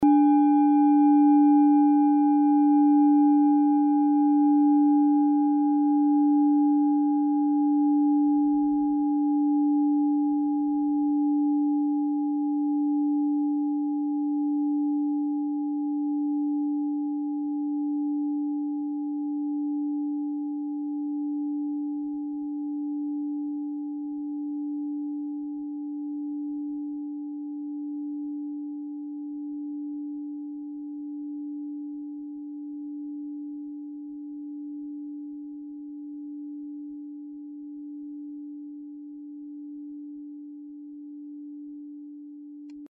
Klangschale Nr.4 Bengalen
Diese Klangschale ist eine Handarbeit aus Bengalen. Sie ist neu und wurde gezielt nach altem 7-Metalle-Rezept in Handarbeit gezogen und gehämmert.
Hörprobe der Klangschale
Filzklöppel oder Gummikernschlegel
In unserer Tonleiter liegt dieser Ton nahe beim "D".
klangschale-ladakh-4.mp3